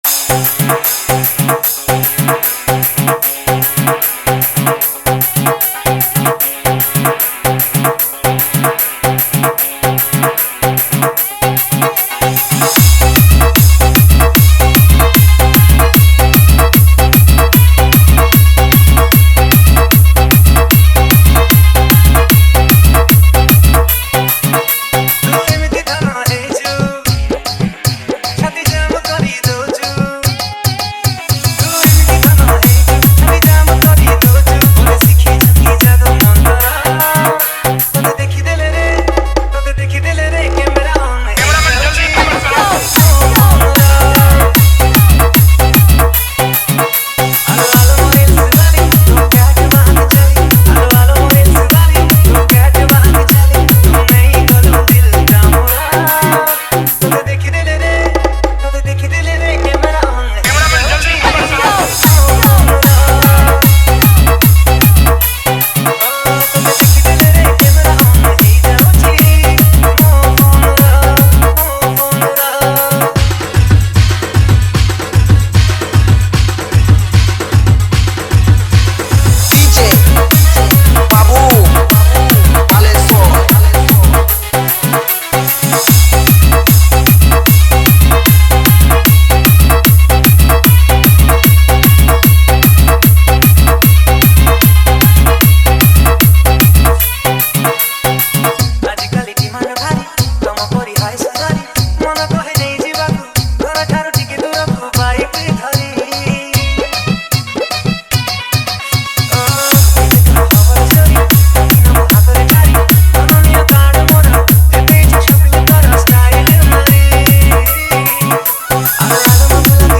Category:  New Odia Dj Song 2024